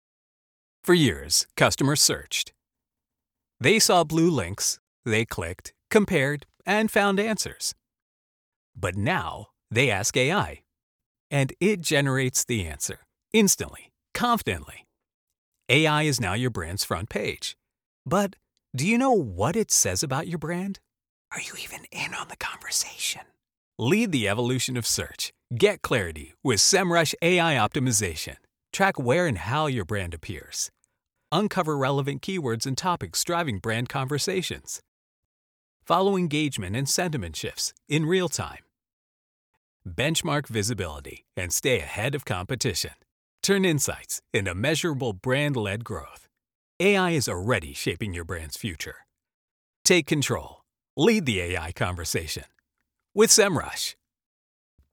Englisch (Amerikanisch)
Kommerziell, Natürlich, Unverwechselbar, Zugänglich, Vielseitig
Unternehmensvideo